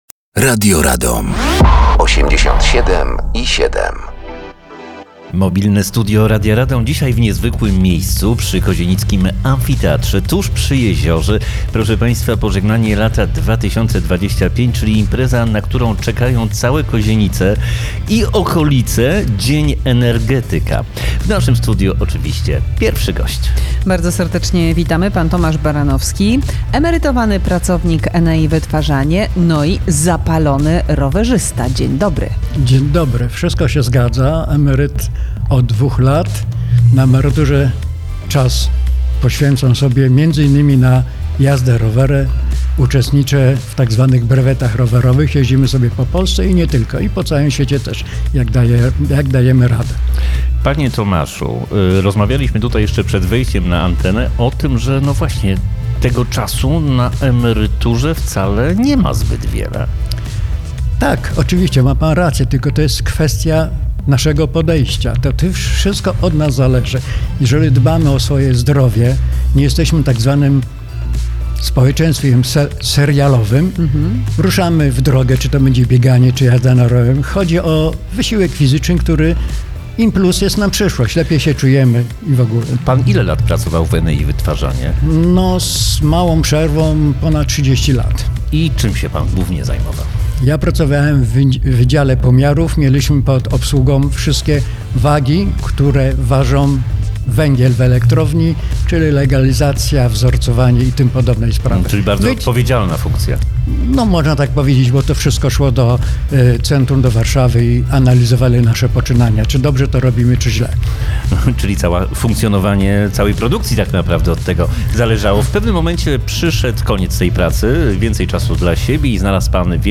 Na miejscu obecne jest Mobilne Studio Radia Radom, a w nim tradycyjnie mnóstwo gości.